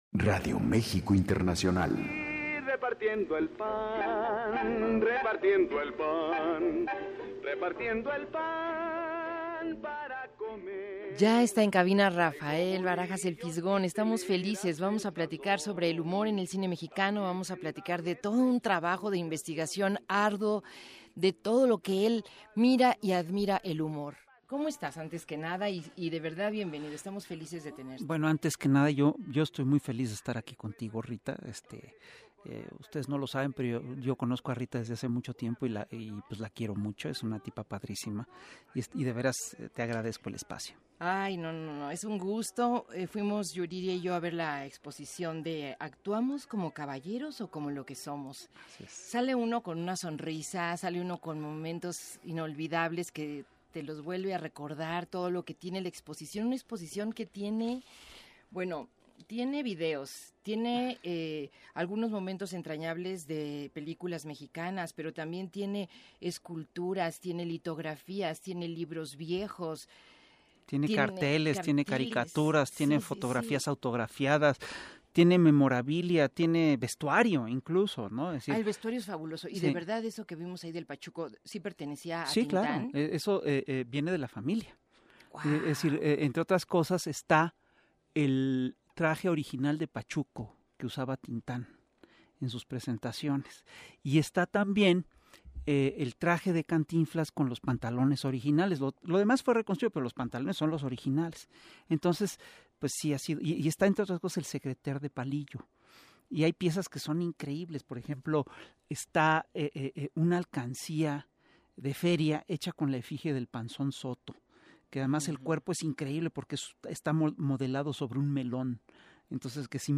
Escucha la entrevista con Rafael Barajas “El Fisgón”…